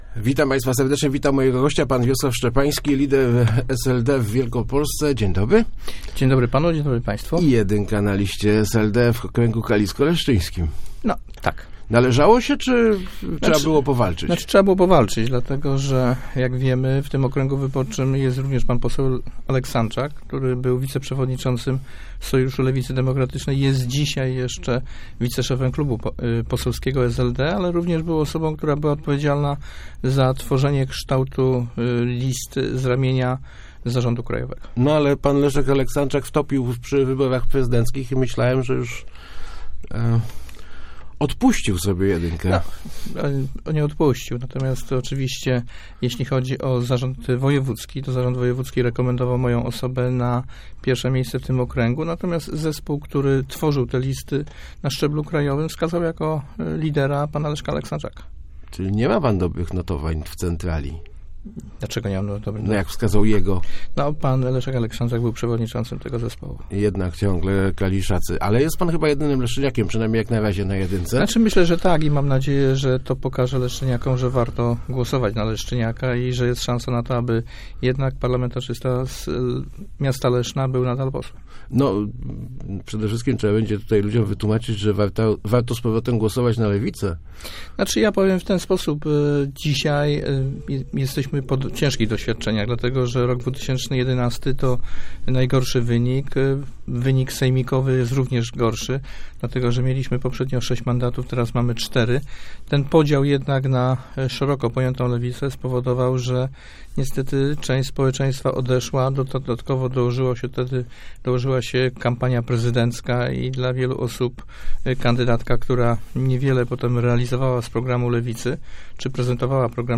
Nasze wyborcze kl�ski w ostatnich latach wynika�y w du�ej mierze z naszego rozproszenia, dlatego Zjednoczona Lewica jest szans� na dobry wynik wyborczy - mówi� w Rozmowach Elki Wies�aw Szczepa�ski, lider wielkopolskiego SLD.